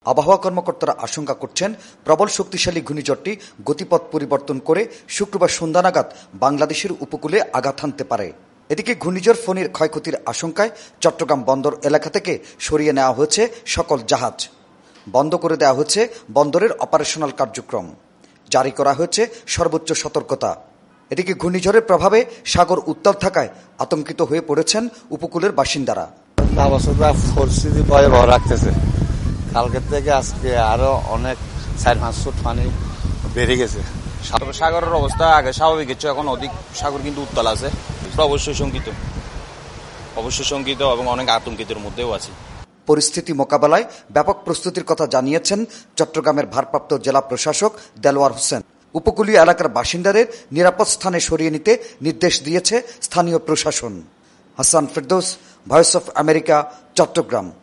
চট্টগ্রাম থেকে